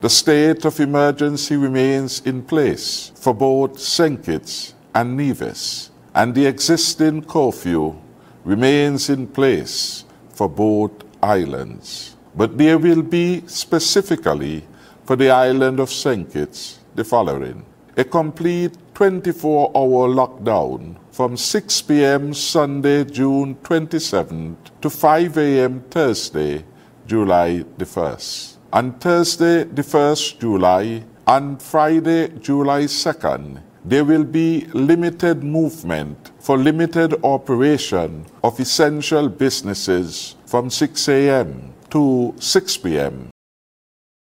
Prime Minister, Dr. Timothy Harris in a national address on June 25th, announced additional stricter measures to limit the spread of the COVID-19 virus.